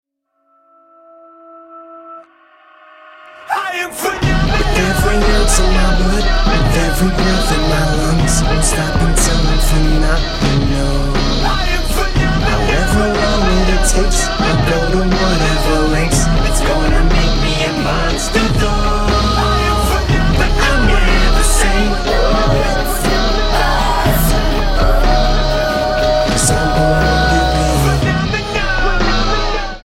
• Качество: 128, Stereo
Хип-хоп
саундтреки
крутые
тревожные